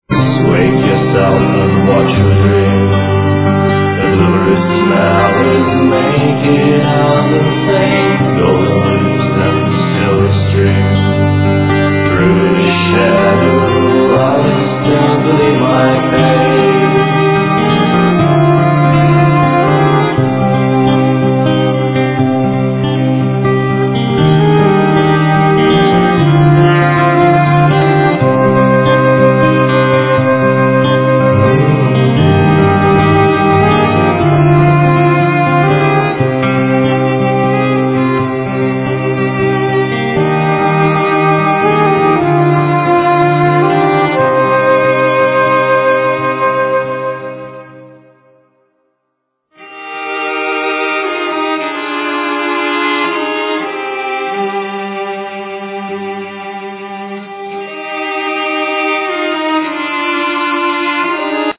Voices
Keyboards, Flute
Cello
Violin
Guitars
Voices, Sounds
Voice, Guitar